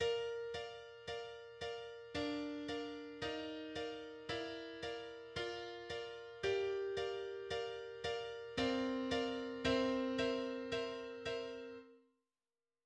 4 time and features a melody played by the violas and accompanied by the violins: